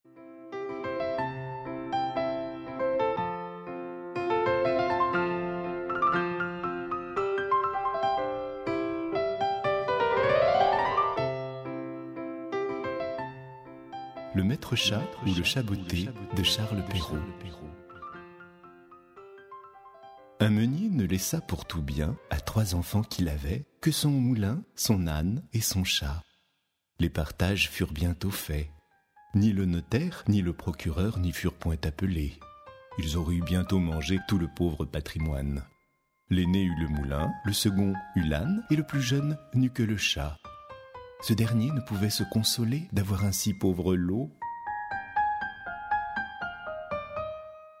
Diffusion distribution ebook et livre audio - Catalogue livres numériques
Une illustration sonore accompagne la lecture audio.